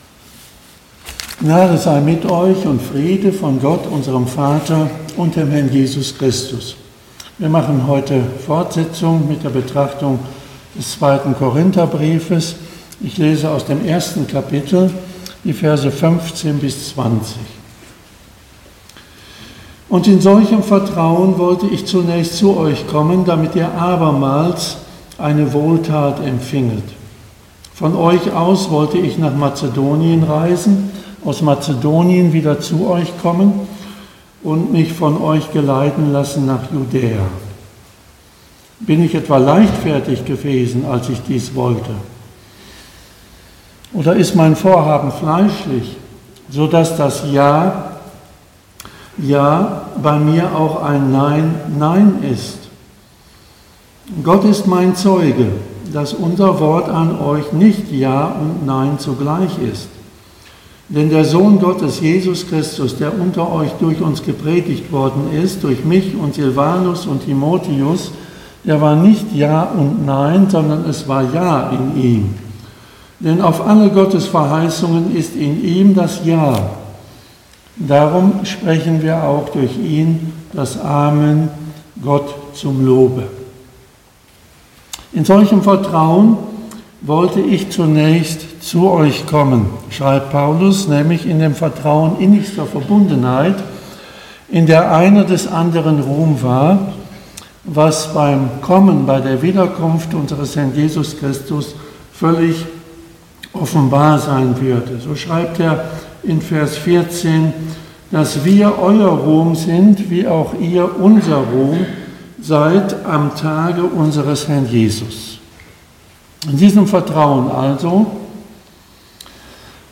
Predigten 2022